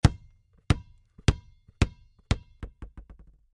luodi.wav